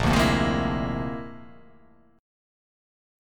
A#9b5 chord